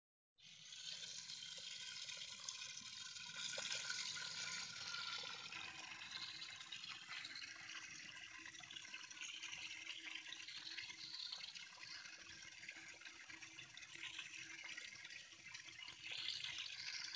• immagini e audio “presi” sul posto dal Ccrr.
IL SUONO DEL FONTANINO
SUONO-FONTANINO.m4a